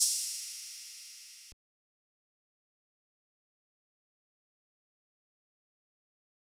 Open Hats
Metro Openhats [Mafia].wav